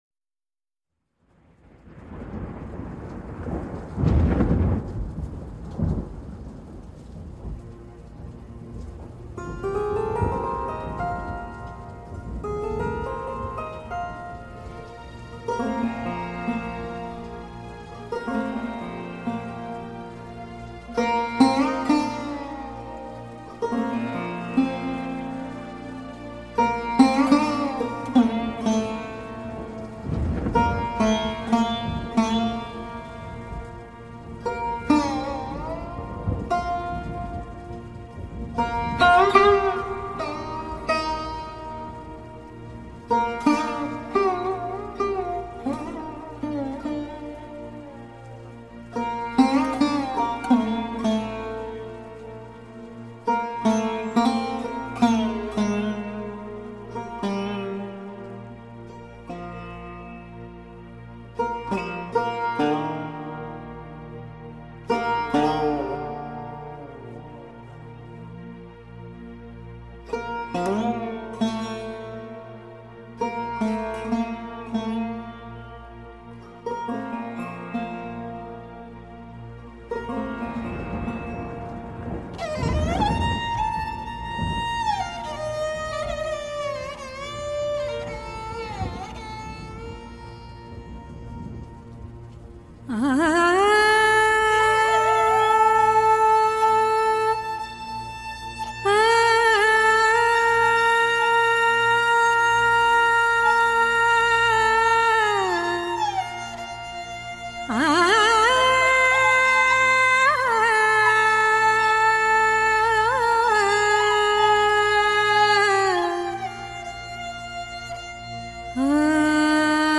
Occupation : Hindustani Classical Vocalist
Nazrul song
" A magical voice filled with
" pure-colourful microtonal contralto